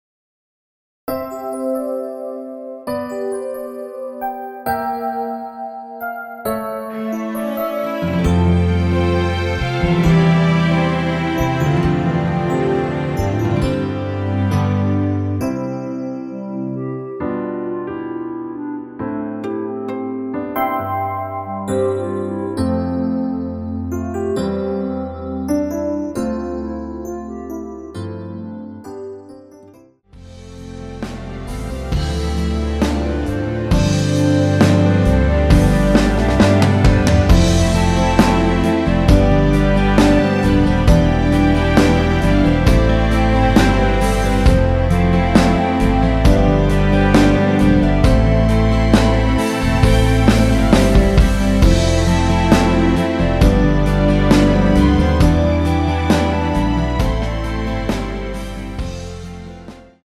원키에서(-2)내린 멜로디 포함된 MR입니다.
◈ 곡명 옆 (-1)은 반음 내림, (+1)은 반음 올림 입니다.
앞부분30초, 뒷부분30초씩 편집해서 올려 드리고 있습니다.
중간에 음이 끈어지고 다시 나오는 이유는